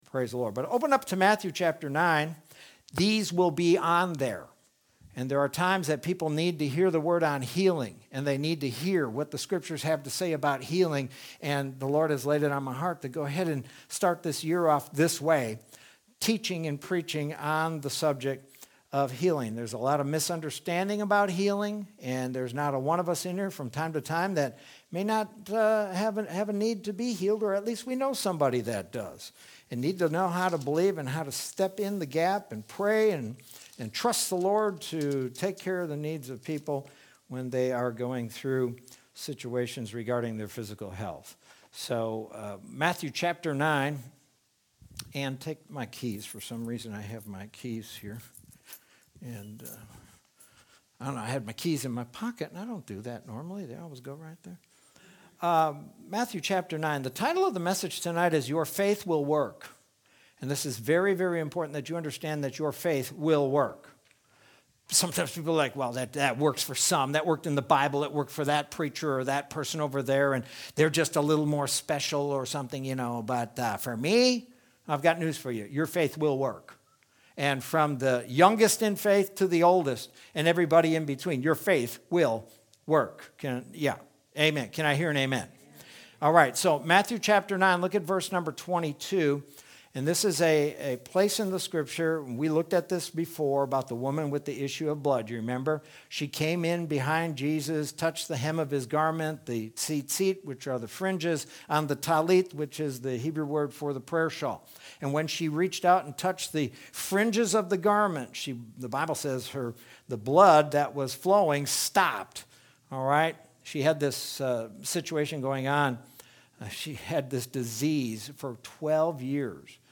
Sermon from Wednesday, February 10th, 2021.